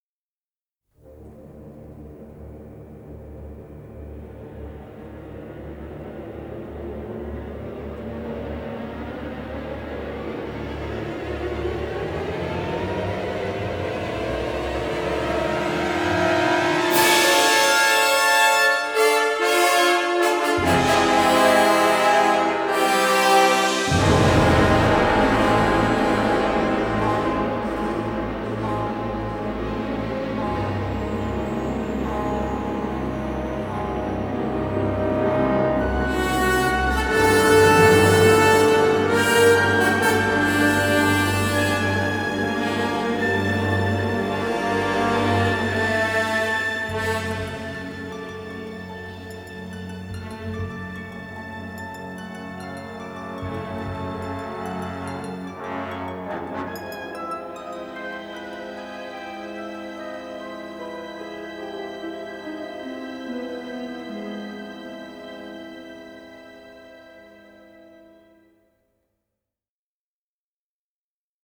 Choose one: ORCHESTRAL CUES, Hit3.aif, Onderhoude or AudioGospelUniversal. ORCHESTRAL CUES